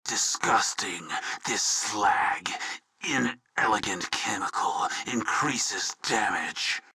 Processed_VOBD_Assassin_GEN_React_Pain_Slag_02
Category: Games   Right: Personal